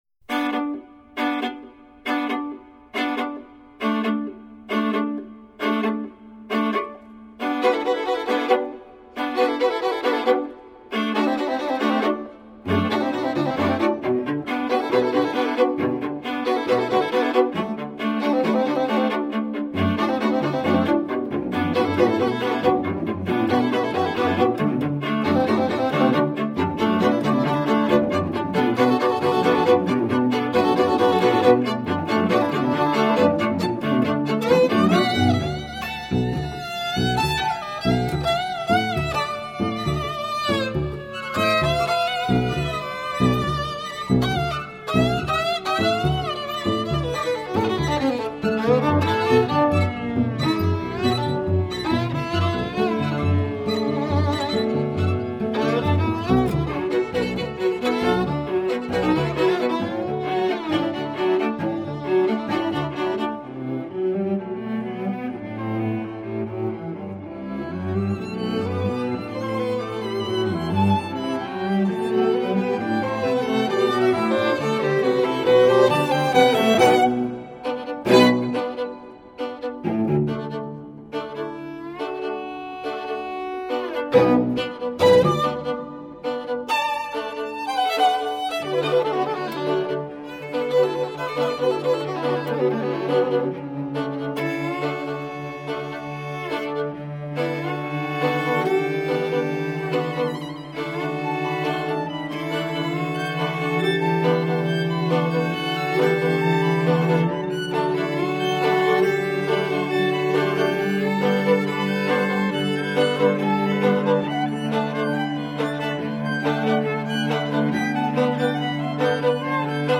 1st violin